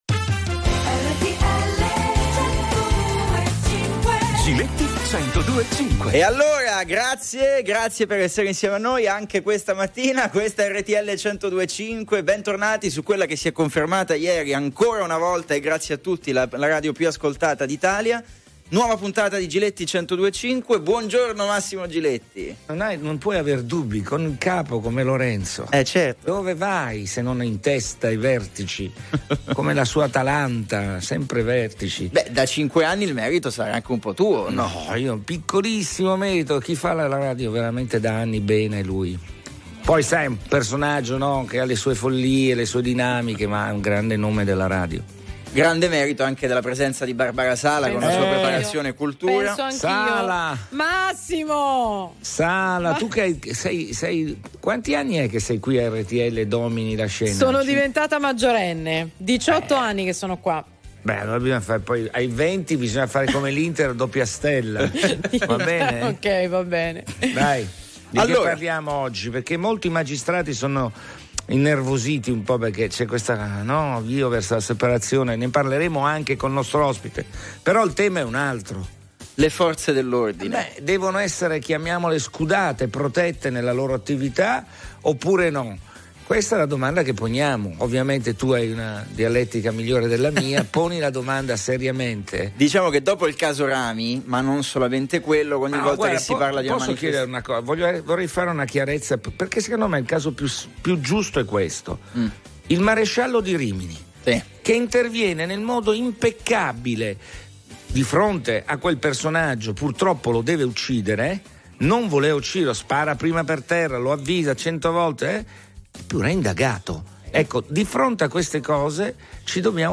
Telefoni aperti ai Very Normal People sui fatti della settimana. Il tema di oggi è la tutela delle forze dell’ordine.